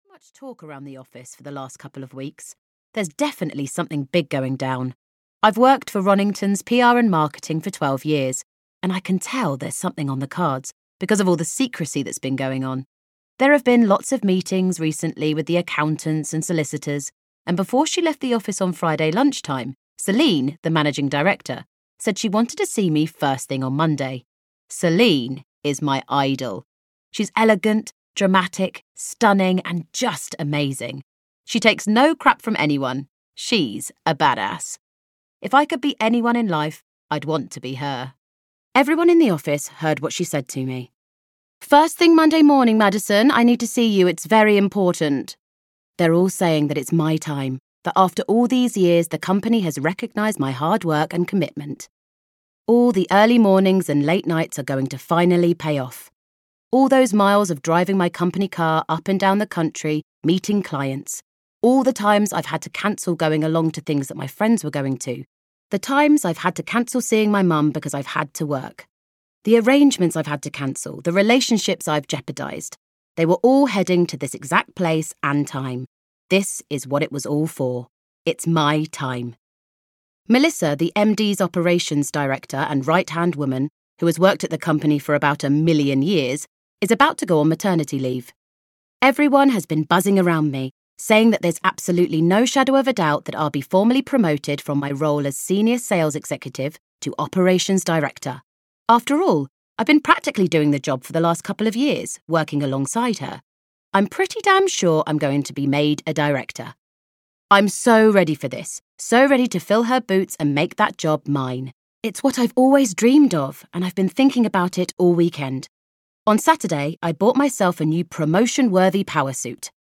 Audio knihaEscape to Giddywell Grange (EN)
Ukázka z knihy